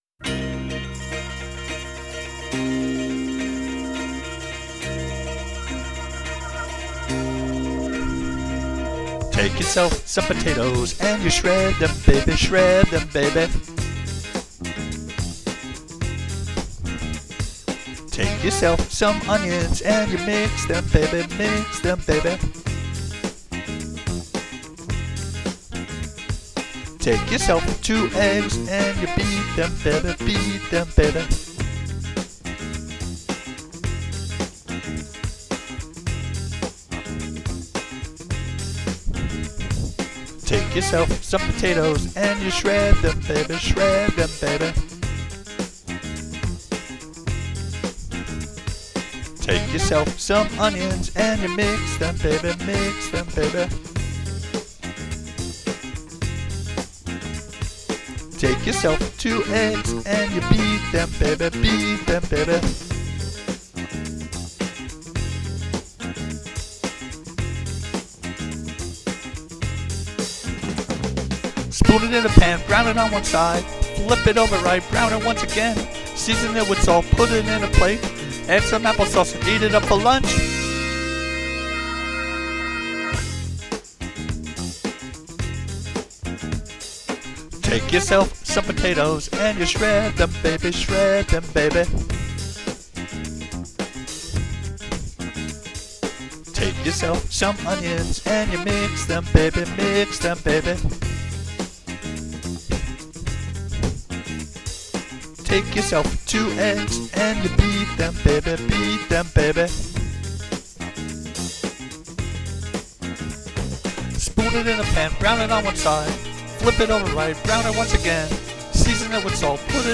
It's tuneful (sort of) and instructional too.